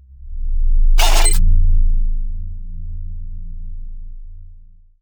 UI_SFX_Pack_61_33.wav